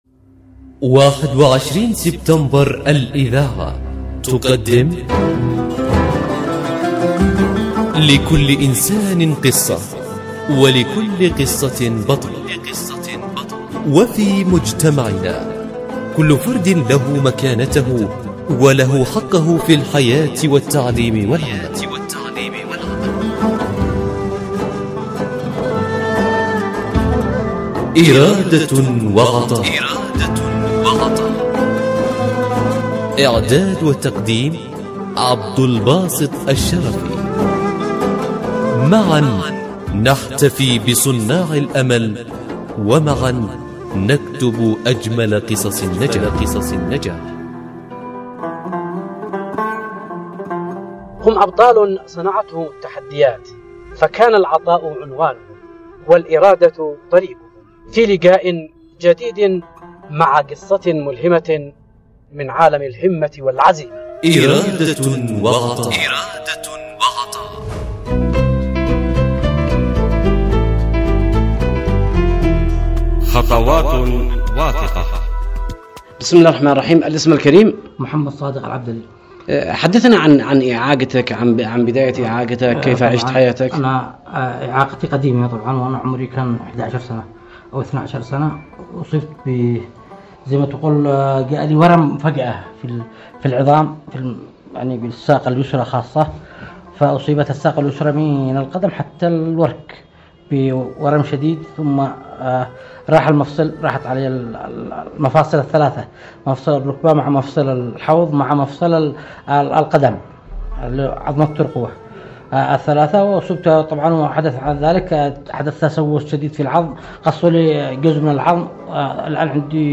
برنامج “إرادة وعطاء” يأخذكم في رحلة إذاعية قصيرة ، نستكشف خلالها عالماً مليئاً بالتحدي والإصرار. نسلط الضوء على قصص ملهمة لأشخاص من ذوي الهمم، أثبتوا أن الإعاقة لا تحد من العطاء بل تزيده قوة وإبداعاً.